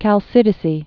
(kăl-sĭdĭ-sē) also Chal·ki·di·kí (häl-kēthē-kē, äl-)